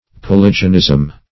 Search Result for " polygenism" : The Collaborative International Dictionary of English v.0.48: Polygenism \Po*lyg"e*nism\ (p[-o]*l[i^]j"[-e]*n[i^]z'm), n. [Cf. F. polyg['e]nisme.]